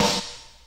• 80's Sparkly Rap Snare Drum Sample A# Key 17.wav
Royality free snare drum tuned to the A# note. Loudest frequency: 3366Hz
80s-sparkly-rap-snare-drum-sample-a-sharp-key-17-ts9.wav